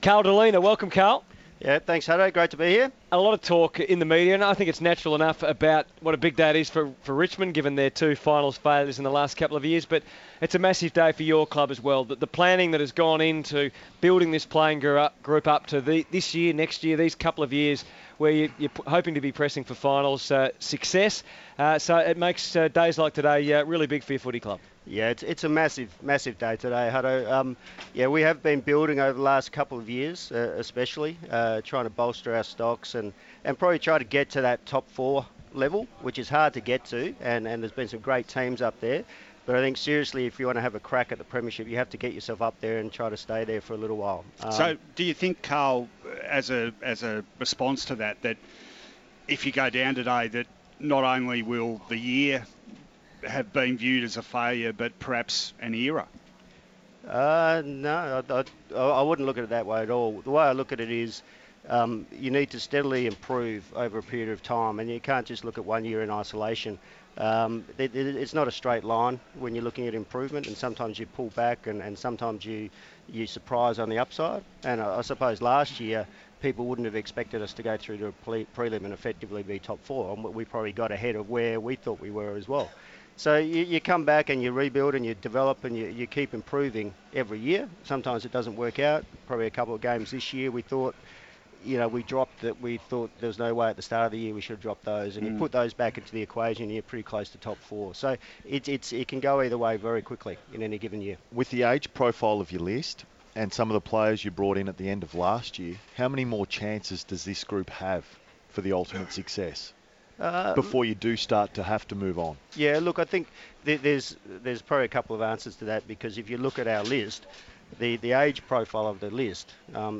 in the commentary box